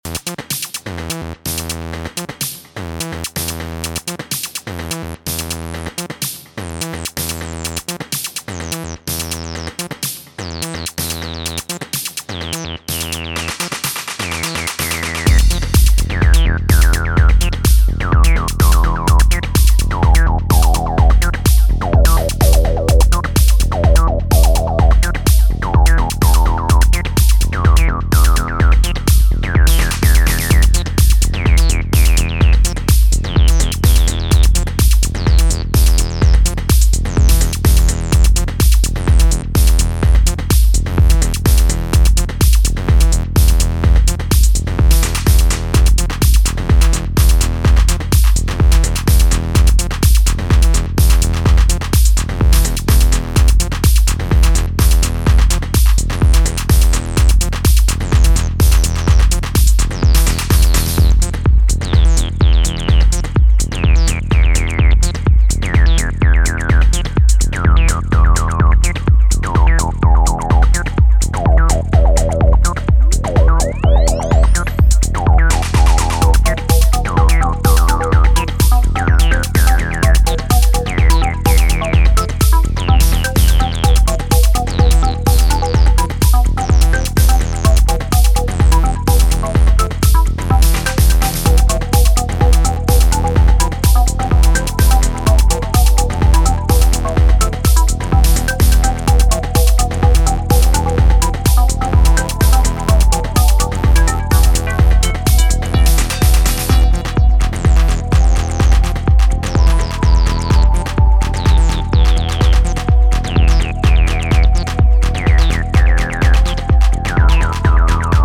for another trip into tech house